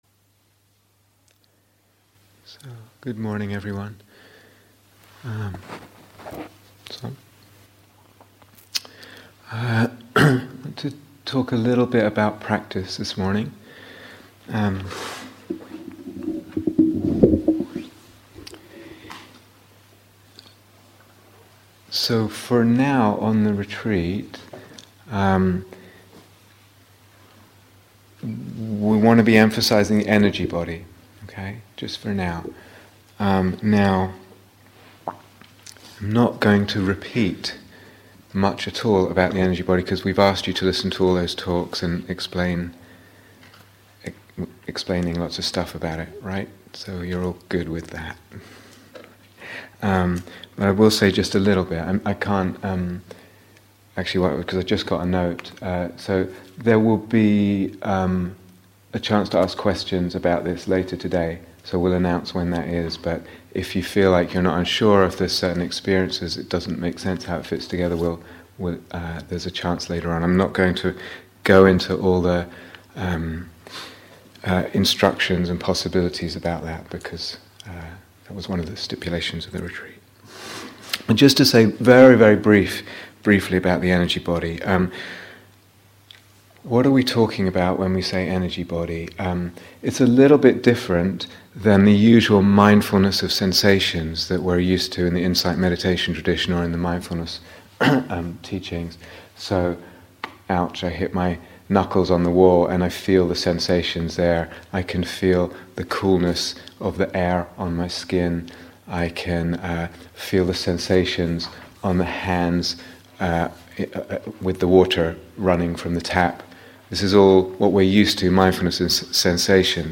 On Orientations in Practice (live and shortened version)